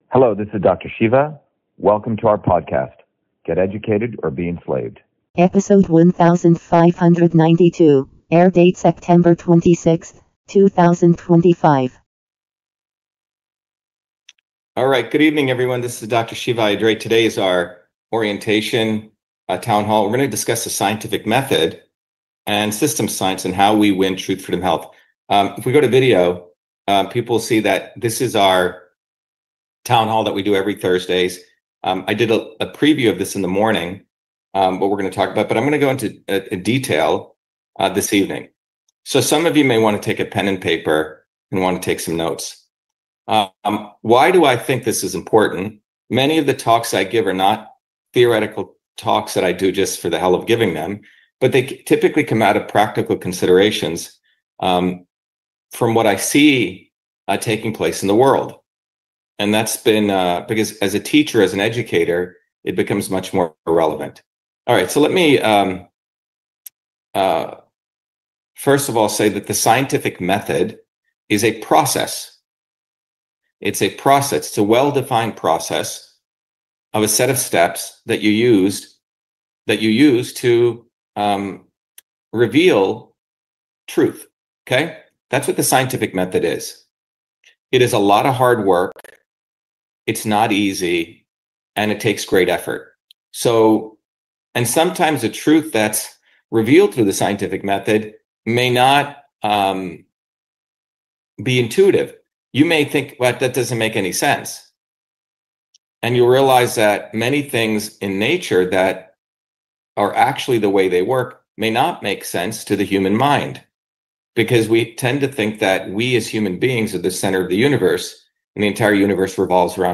In this interview, Dr.SHIVA Ayyadurai, MIT PhD, Inventor of Email, Scientist, Engineer and Candidate for President, Talks about SCIENTIFIC METHOD and Systems Science.